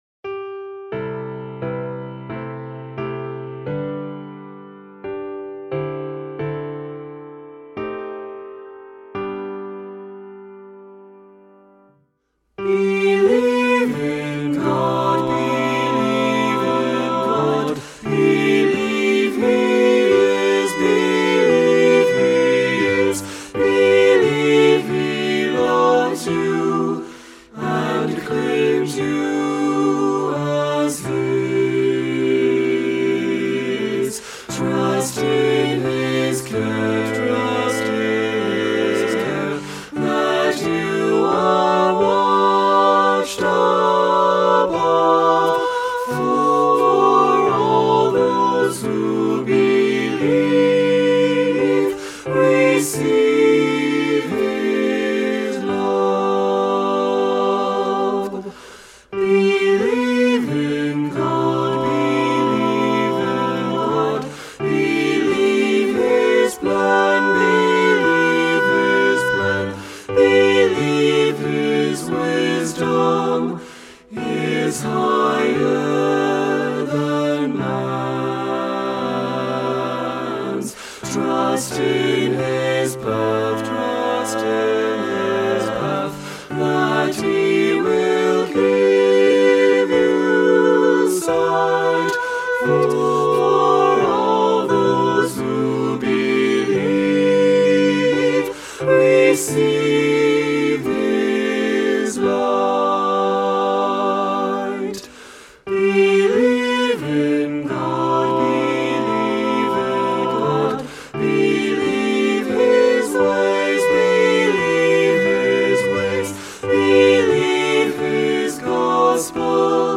SATB Hymn
SATB Traditional Hymn